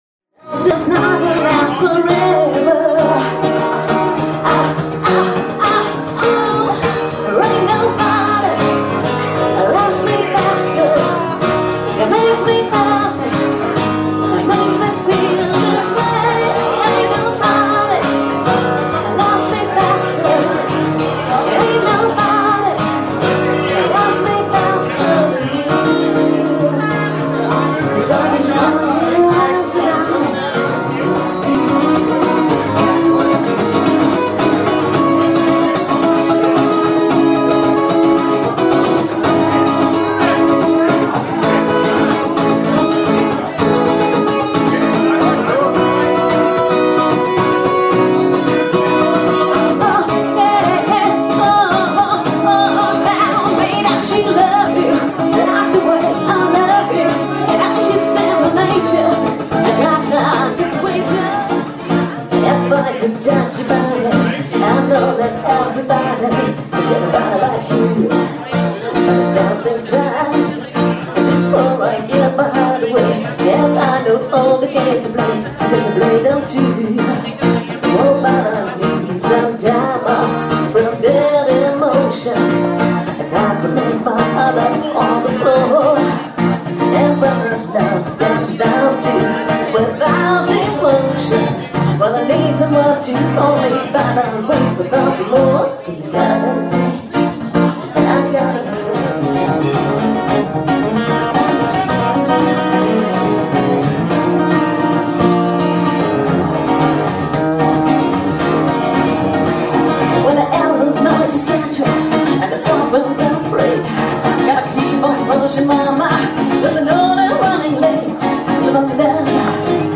(Cover-Genres: Rock/Funk/Soul/Blues/Pop)
voc/git/perc.